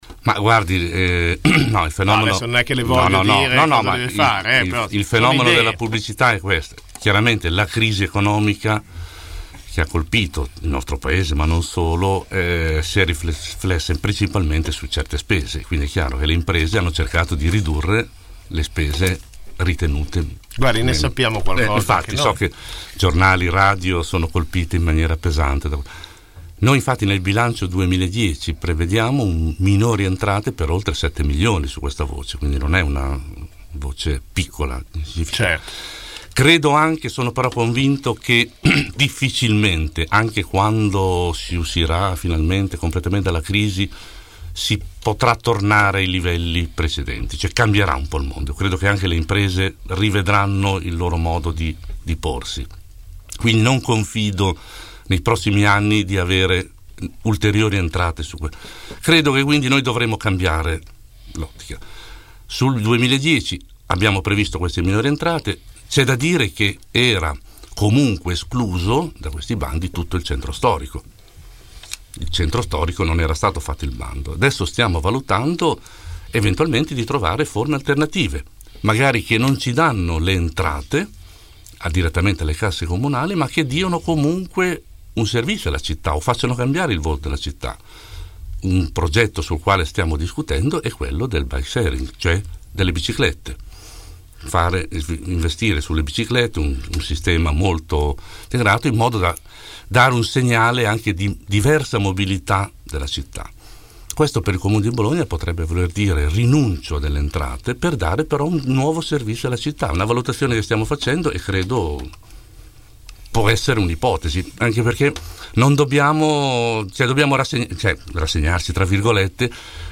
L’assessore al bilancio ai nostri microfoni parla di tagli, tasse e investimenti.